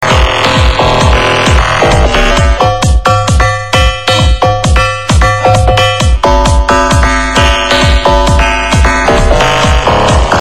Really Nice electro